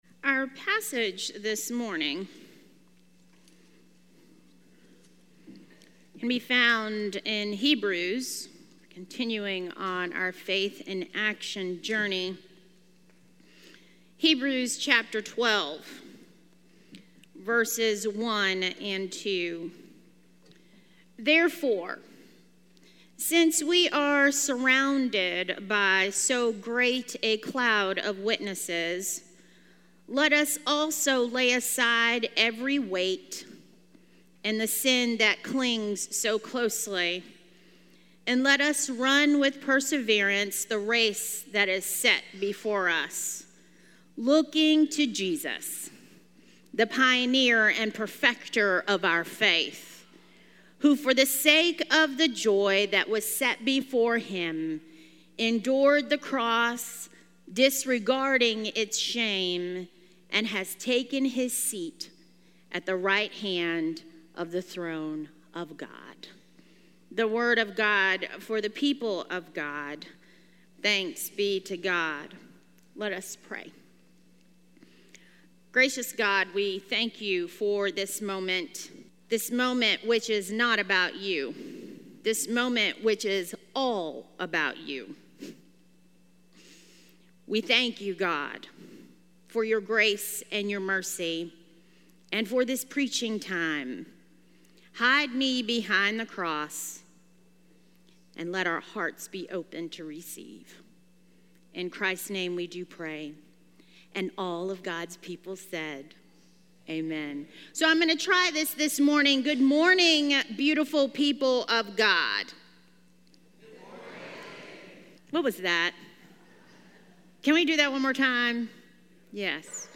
A message from the series "Faith: Action Required."